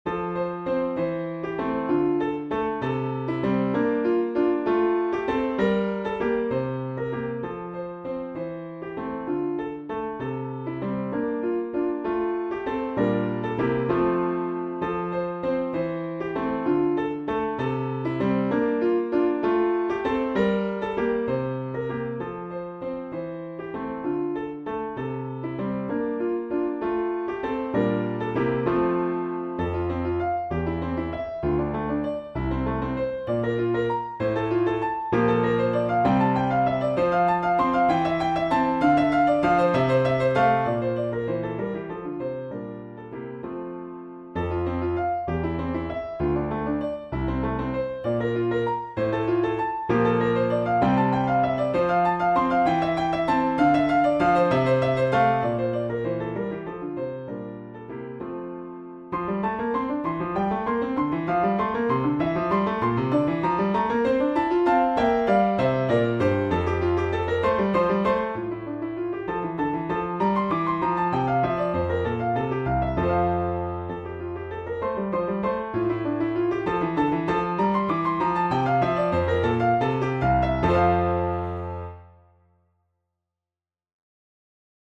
An Intermediate Grade 5 Piano Piece